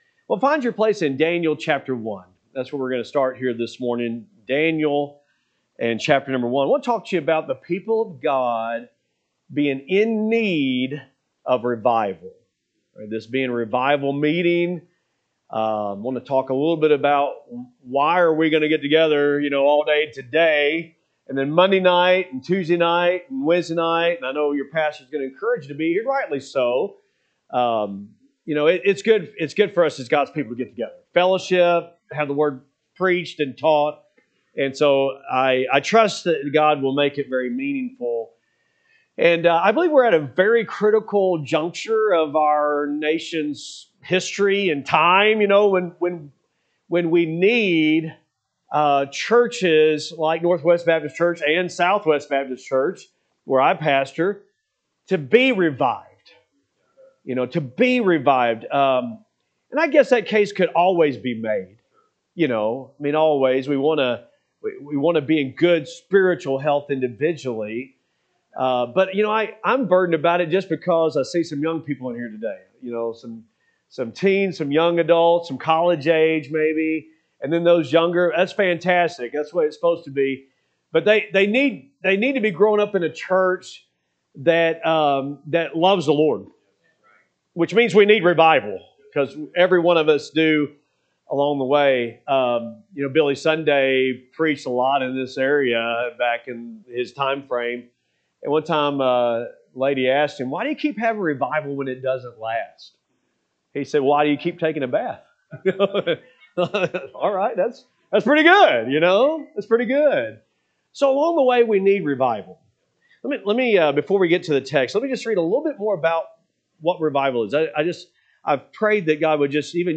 April 12, 2026 Adult Bible Study